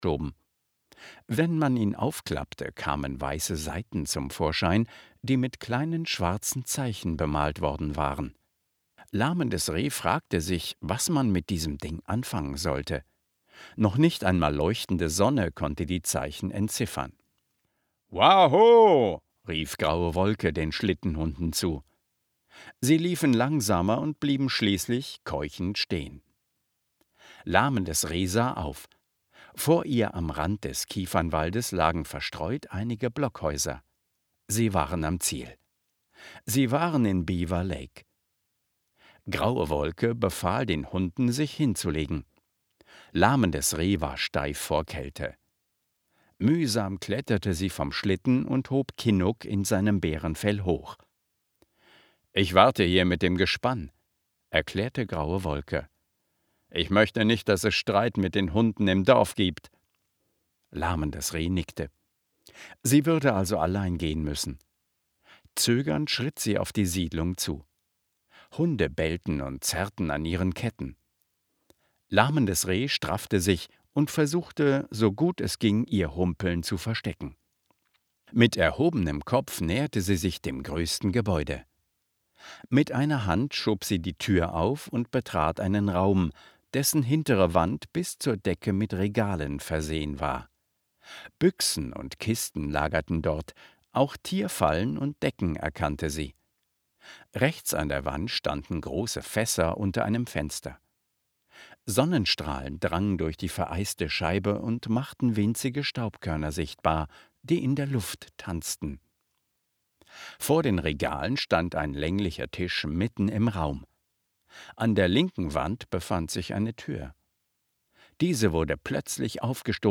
MP3-Hörbuch. Eine berührende Geschichte von Glauben und Vertrauen in einen grossen Gott, der Wunder tut und es gut mit uns meint.